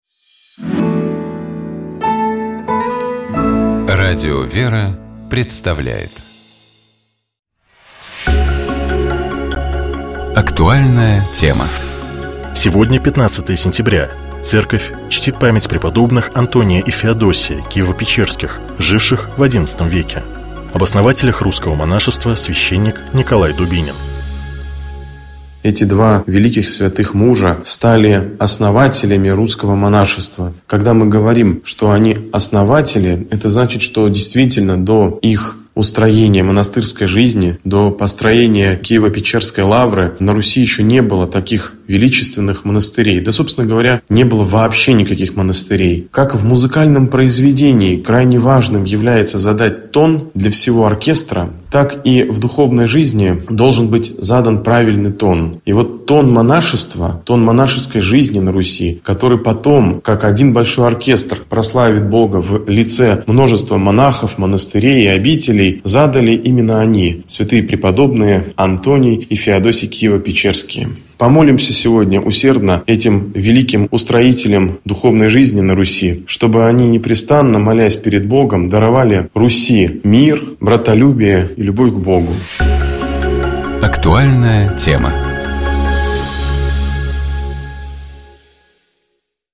Об основателях русского монашества, — священник